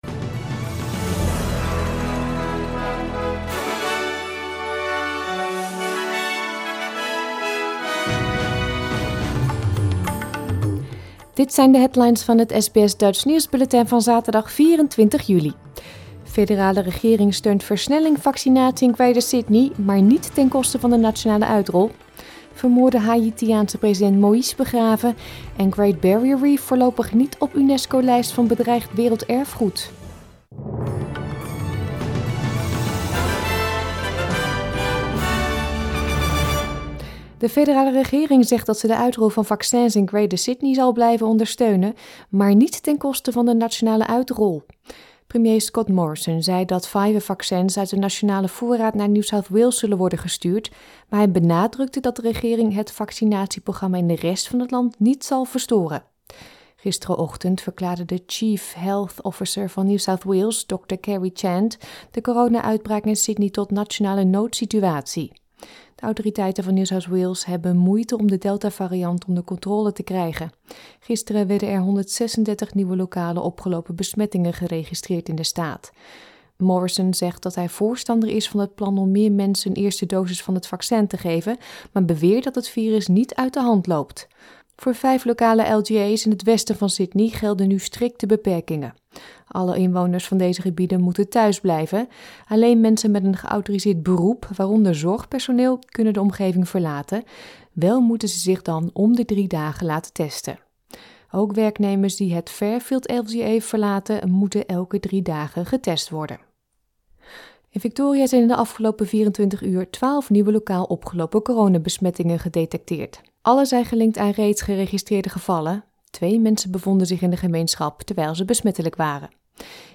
Nederlands/Australisch SBS Dutch nieuwsbulletin van zaterdag 24 juli 2021